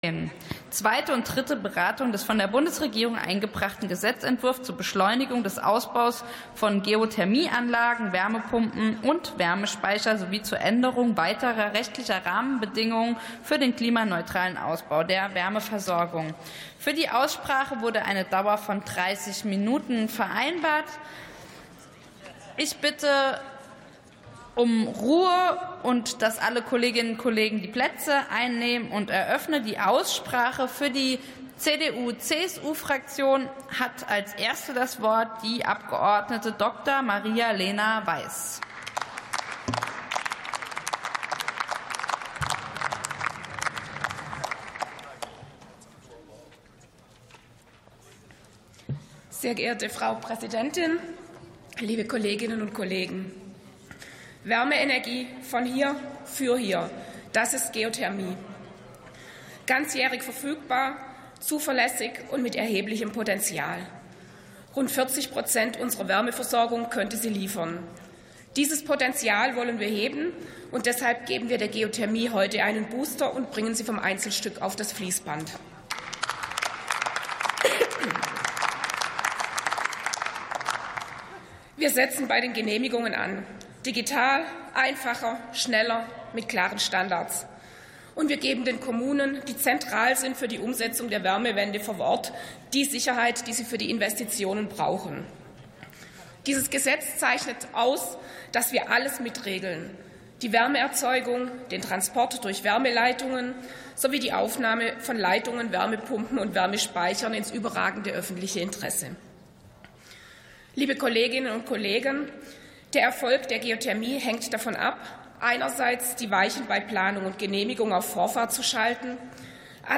Sitzung vom 04.12.2025. TOP 13: Ausbau der Wärmeversorgung ~ Plenarsitzungen - Audio Podcasts Podcast